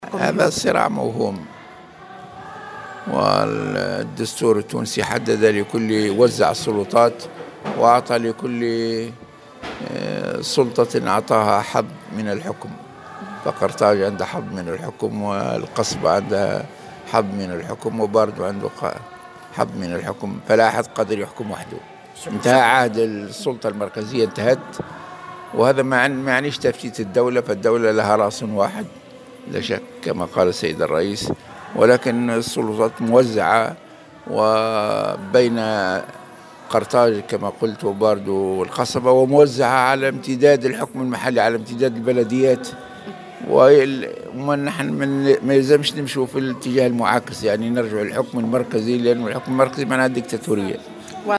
وأوضح في تصريح لموفد "الجوهرة أف أم"، على هامش اجتماع للمكاتب المحلية للحركة في سوسة، أنّ الدستور التونسي وزّع السّلطات ومجالات الحكم ولا أحد يقدر على الحكم لوحده.